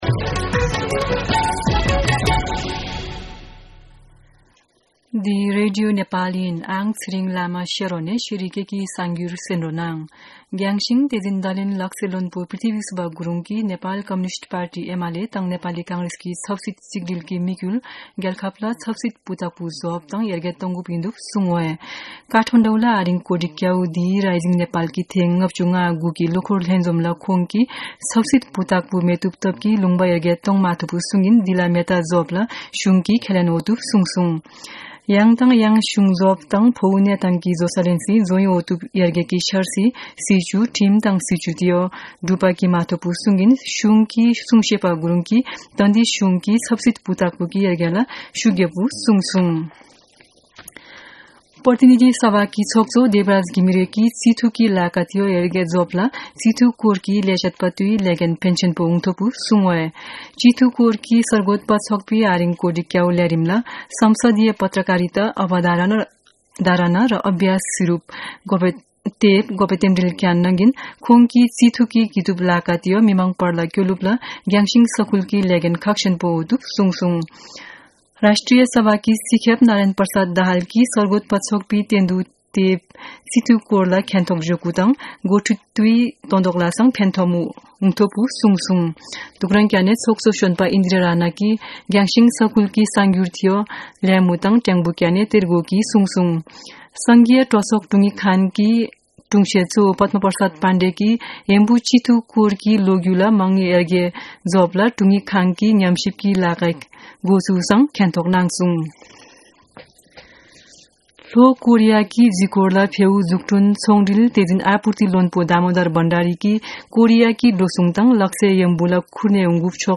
शेर्पा भाषाको समाचार : २ पुष , २०८१
4-pm-Sherpa-news-1-5.mp3